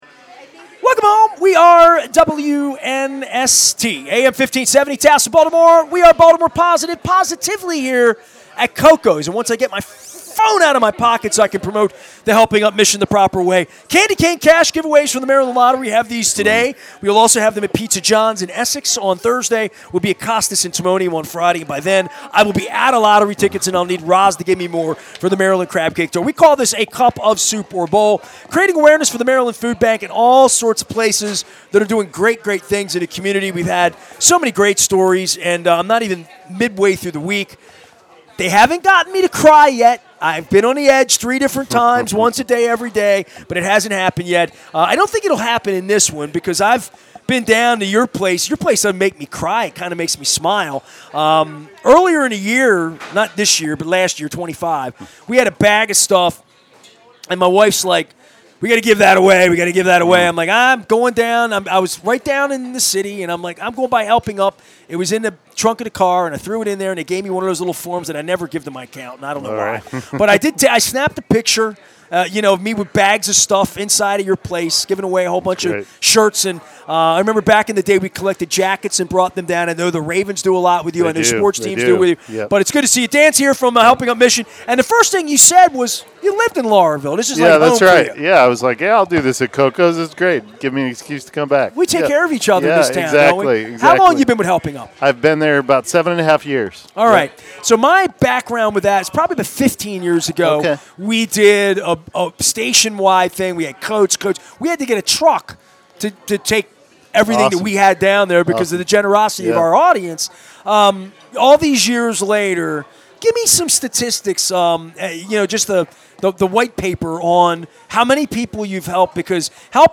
dropped by Koco's Pub on "A Cup Of Soup Or Bowl"